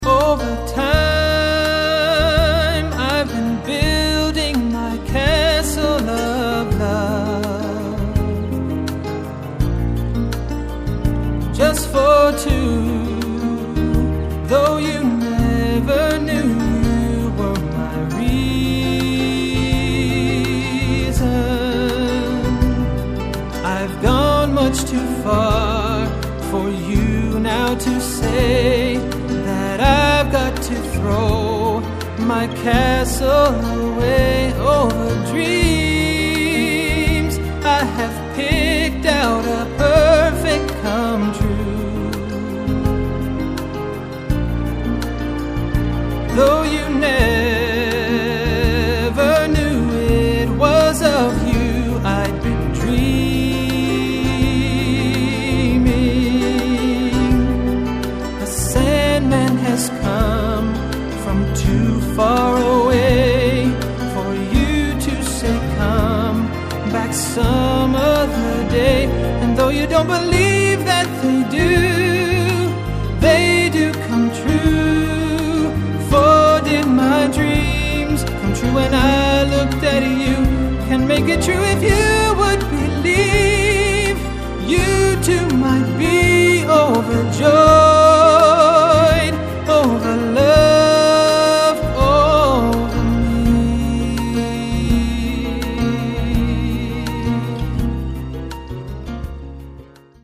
tenor voice